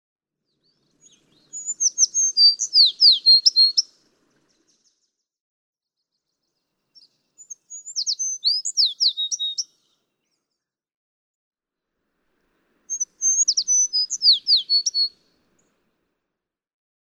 American tree sparrow
First is a composite recording in which you hear only one song from each of three individuals (♫271); longer recordings are available from each of those individuals (♫272, ♫273, ♫274).
♫271—one song from each of three individuals